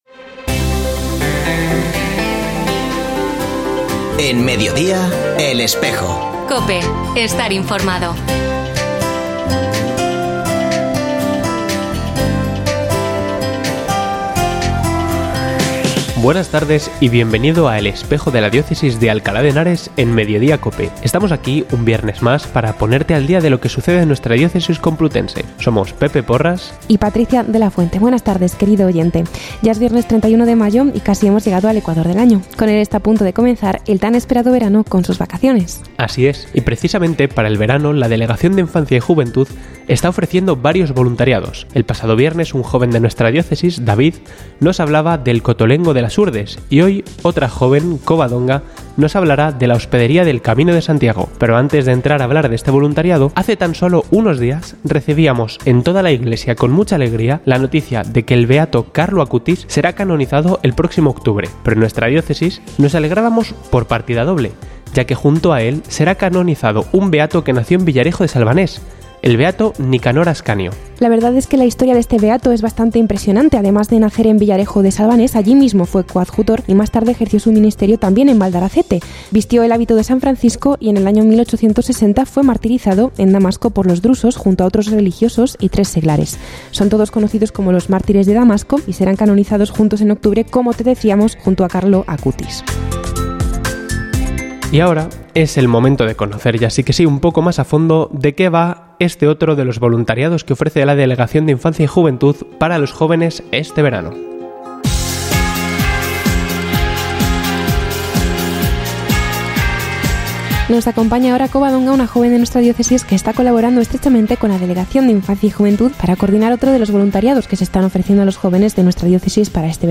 Escucha otras entrevistas de El Espejo de la Diócesis de Alcalá
Ofrecemos el audio del programa de El Espejo de la Diócesis de Alcalá emitido hoy, 31 de mayo de 2024, en radio COPE. Este espacio de información religiosa de nuestra diócesis puede escucharse en la frecuencia 92.0 FM, todos los viernes de 13.33 a 14 horas.